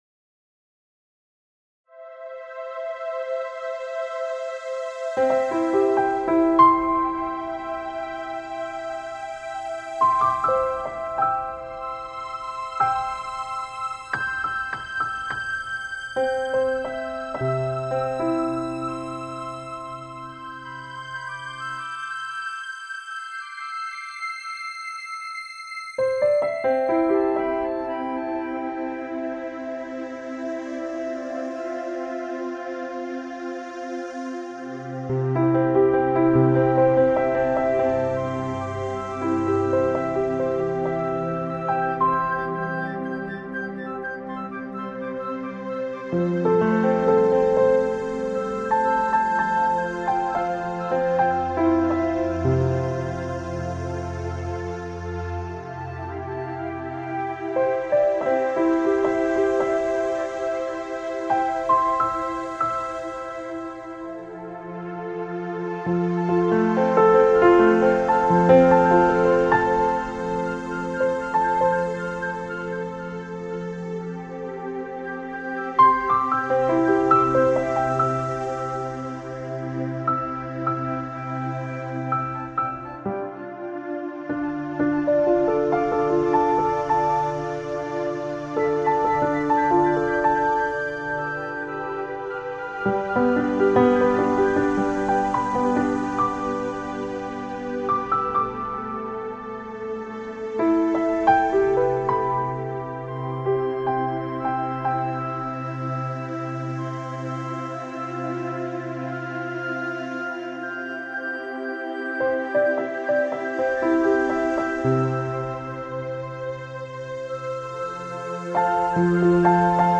am Klavier
Tolles Foto! und so richtig schöne ruhige Musik, genau das Richtige…. heut ist alles ein bisserl ruhiger, weil Kind 1 erst um 20 nach 8 am Bahnhof zum Theaterausflug sein muss… und nicht schon 5 vor 8 in der Schulbank klemmen muss.
Die Klaviermusik spielt er völlig aus dem Augenblick dazu, ich hab eine ganze CD davon.
Ist absichtlich sphärisch, damit man leichter ins Raumbewußtsein gleiten kann.